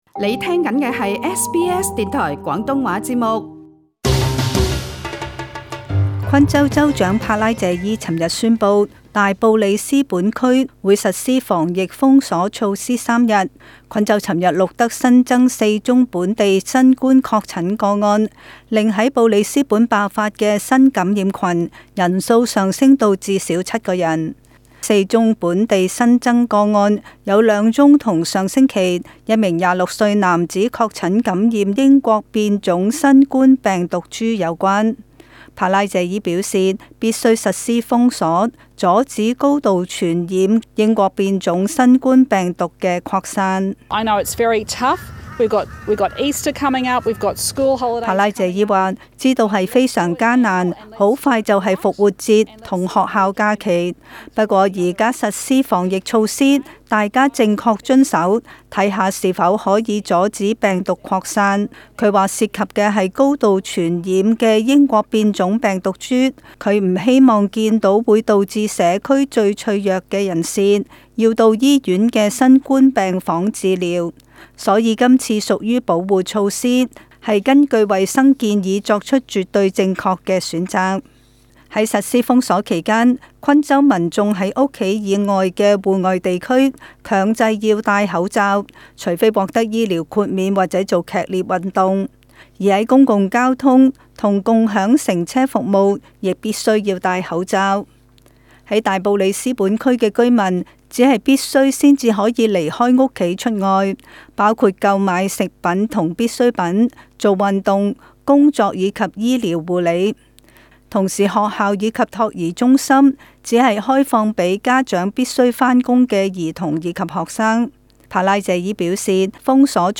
【時事報道】